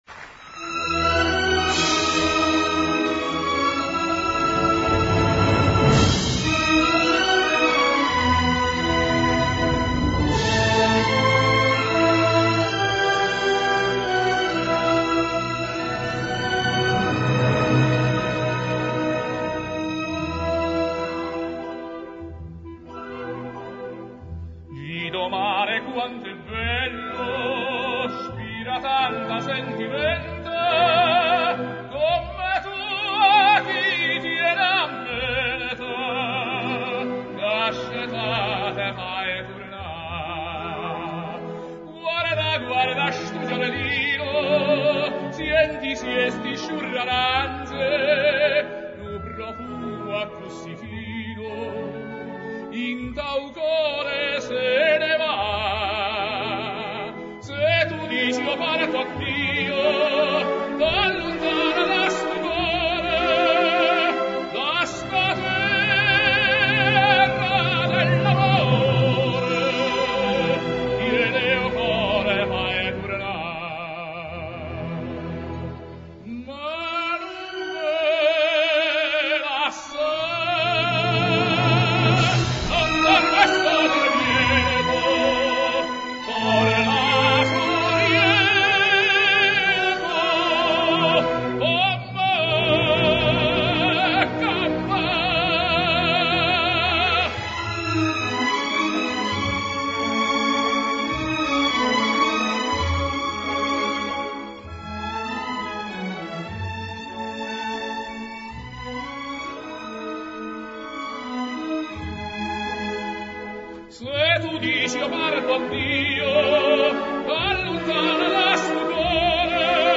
in Concert